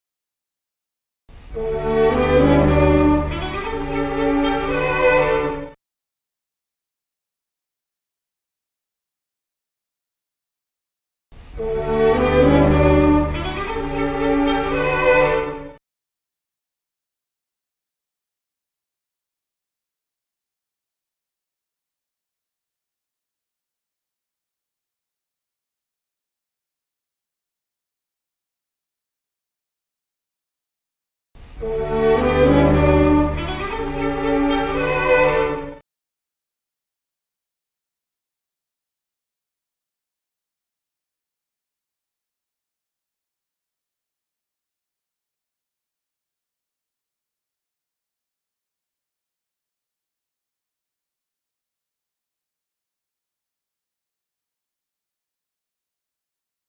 Ett tredje rytmexempel handlar om att avgöra vilken av tre noterade rytmer motsvarar det du hör. Du får höra varje exempel tre gånger efter varandra med allt längre tid emellan.